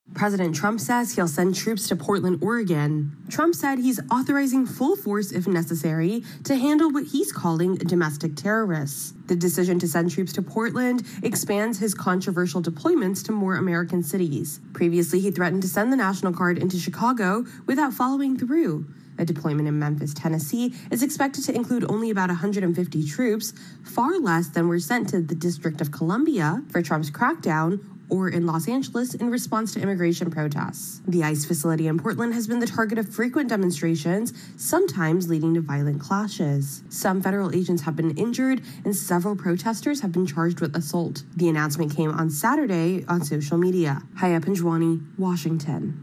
reports on another deployment of troops to an American city.